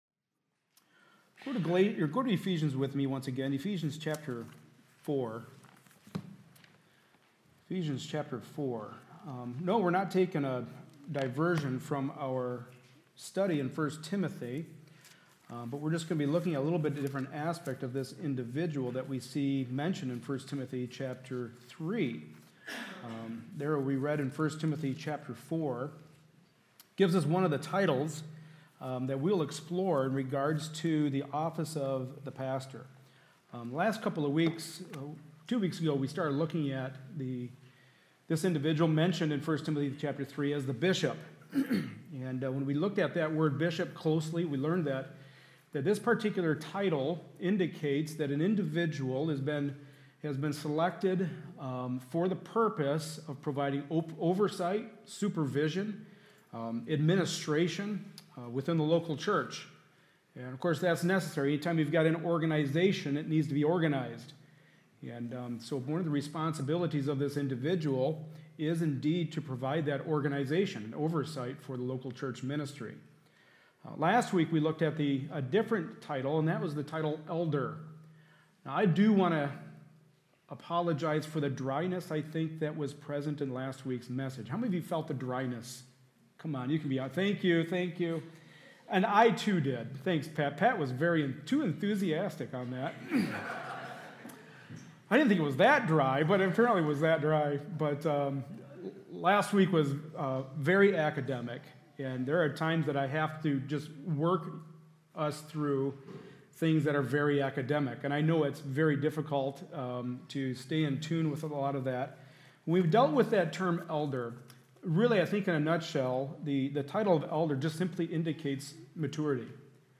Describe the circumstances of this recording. The Book of 1st Timothy Service Type: Sunday Morning Service A study in the pastoral epistles.